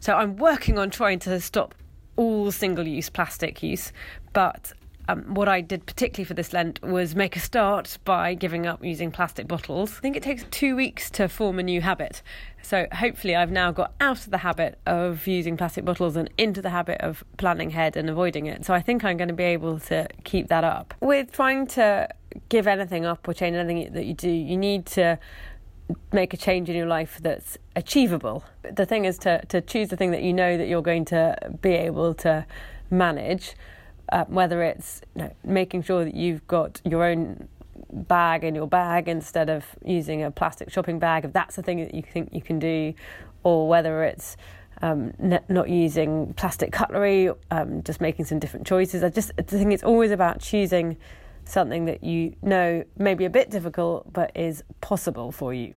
LISTEN: Faversham and Mid Kent MP Helen Whately speaks about giving up plastic bottles for Lent